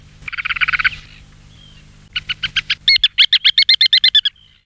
Tournepierre à collier
arenaria interpres
tournepierre.mp3